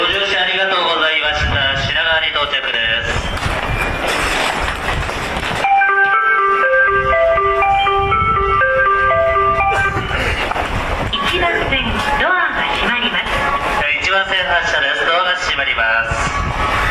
ただ高架下なのでうるさく、一部音質が悪いホームがあり、玉に瑕です。
せせらぎ 普通のメロディです。